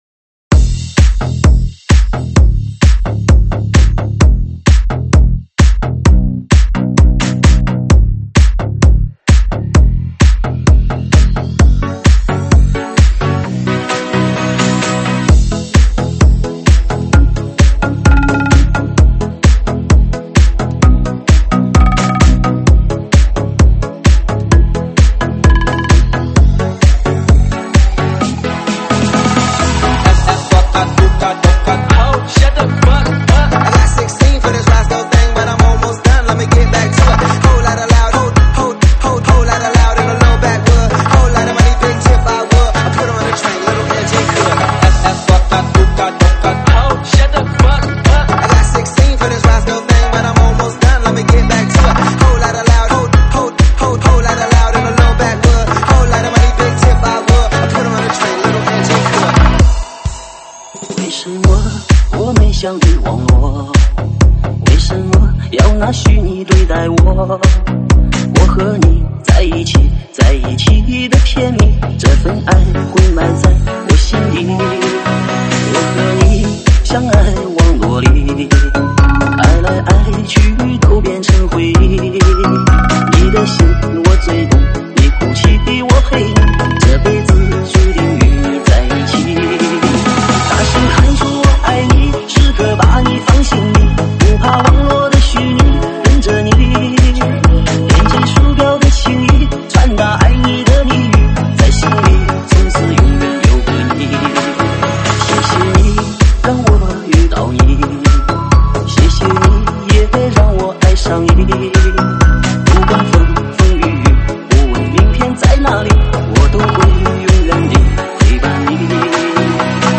【私货专属】车载音乐REMIX 手机播放
舞曲类别：现场串烧